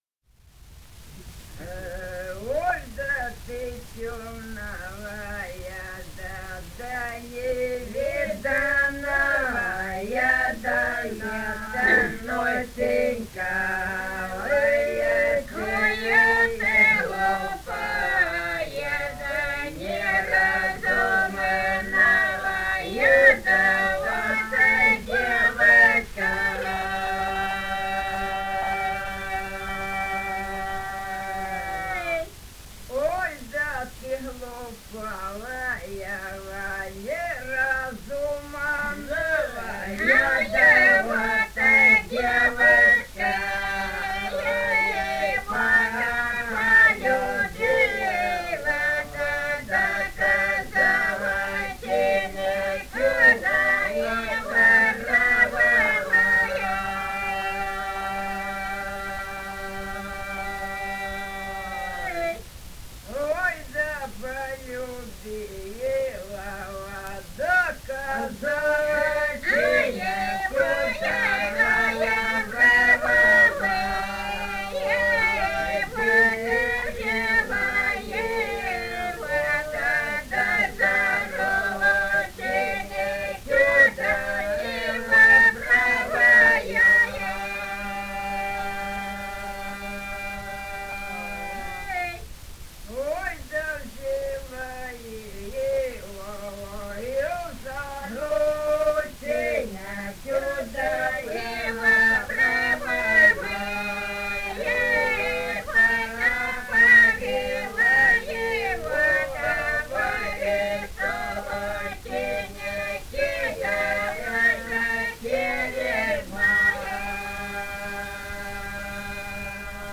полевые материалы
Ростовская область, г. Белая Калитва, 1966 г. И0942-01